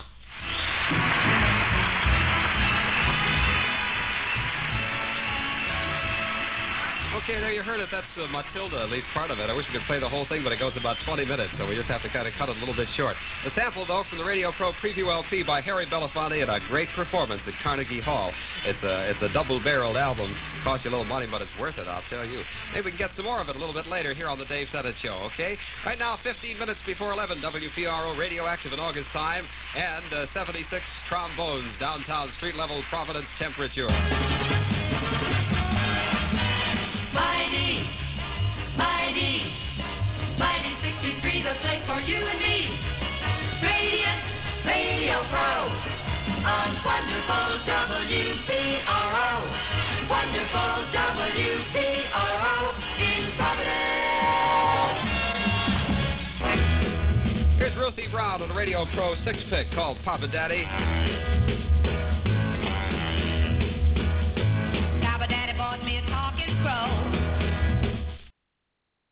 WPRO-FM (92.3), Providence, RI- Simulcast from WPRO-AM (630)